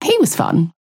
Calico voice line - He was fun.